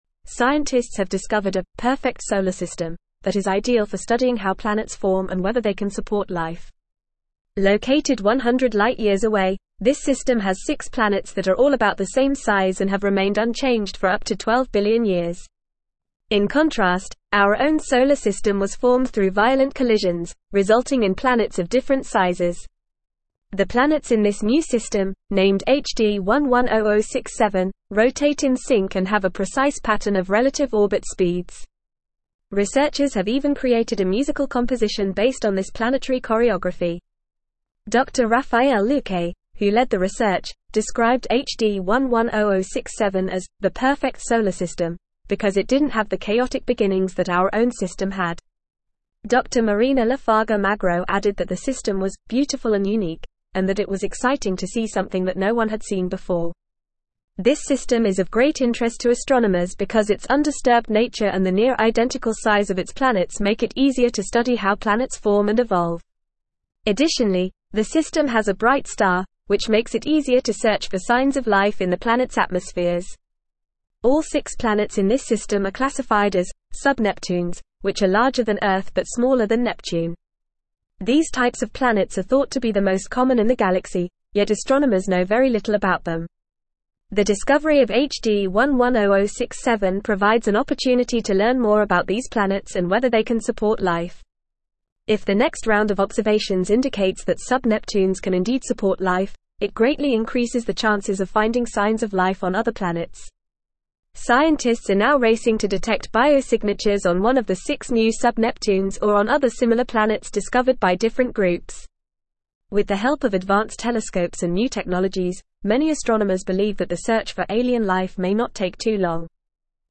Fast
English-Newsroom-Advanced-FAST-Reading-Perfect-Solar-System-Potential-for-Life-and-Discovery.mp3